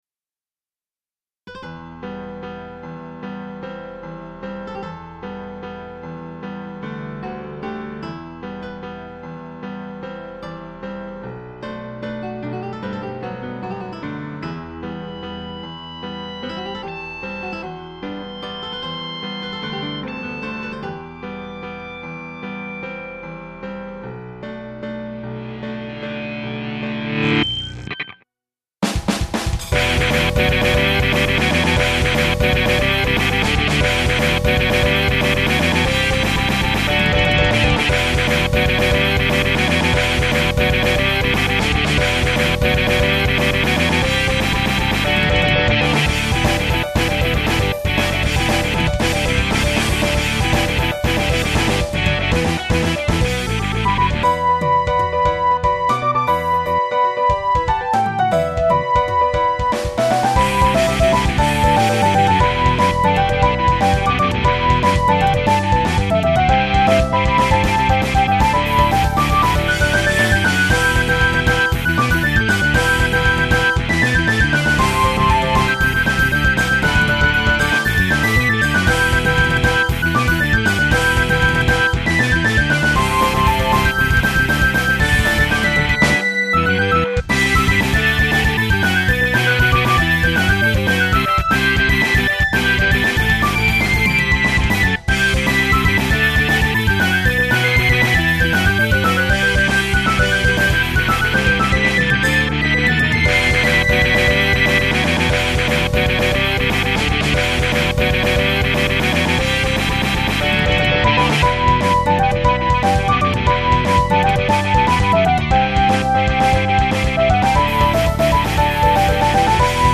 勢いで突っ走るノリの良い曲に仕上がりました(≧∇≦)/ ♪